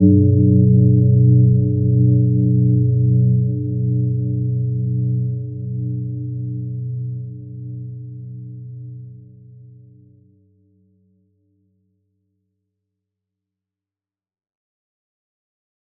Gentle-Metallic-2-B2-mf.wav